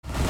• Качество: 320, Stereo
dance
без слов
club
Trance